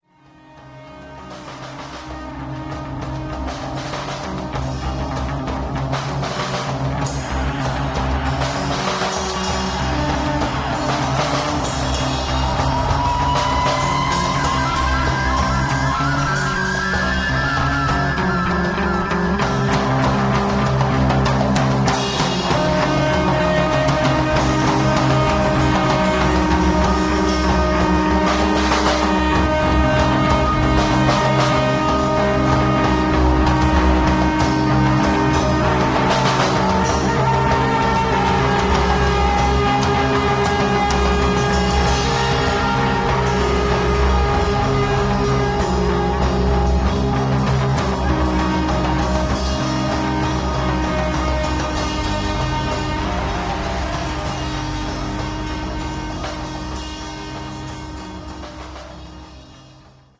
live rock style improvisations